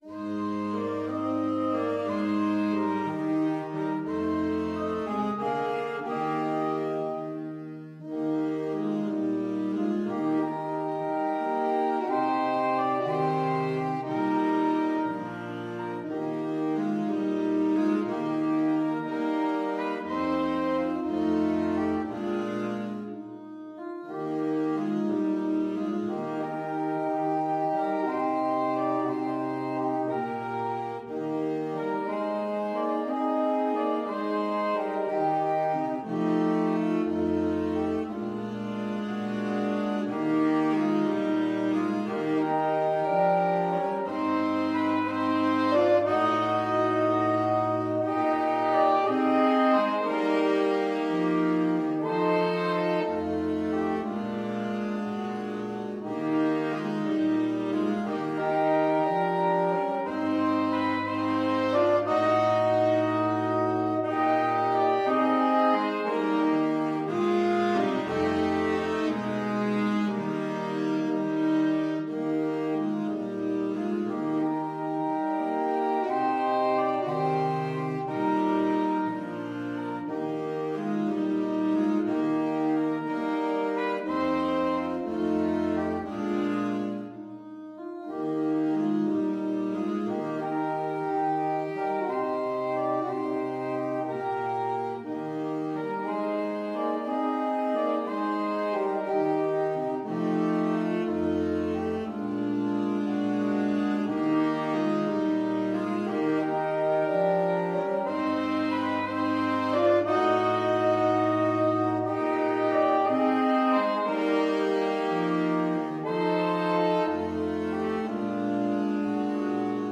Gently and with expression . = c. 60
6/8 (View more 6/8 Music)
Traditional (View more Traditional Saxophone Quartet Music)